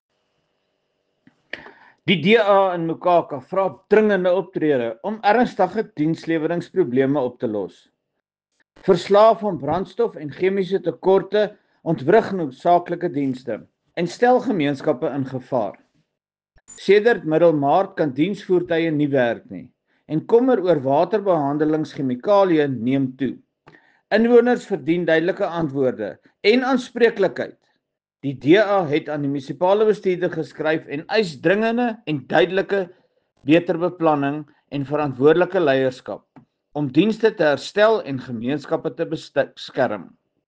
Afrikaans soundbites by Cllr Johan Spaski Geldenhuis.